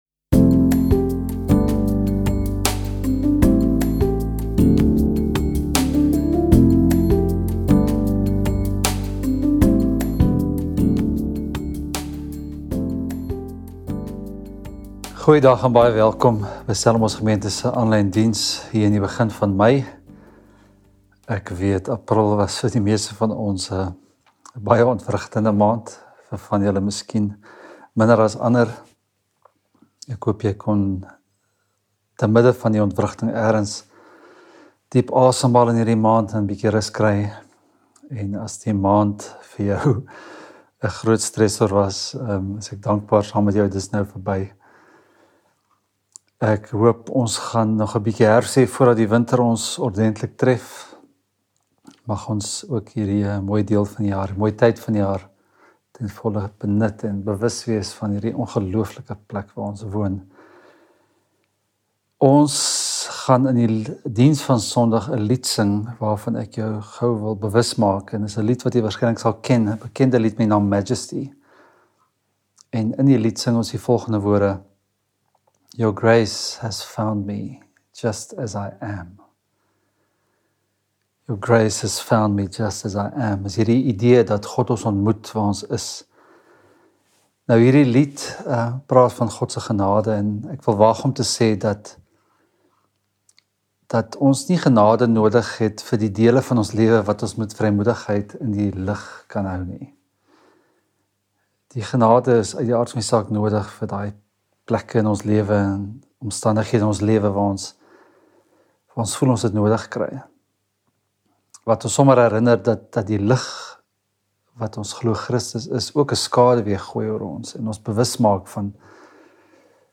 Stellenbosch Gemeente Preke 04 May 2025